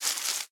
leaves2.ogg